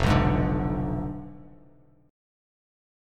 Fdim7 chord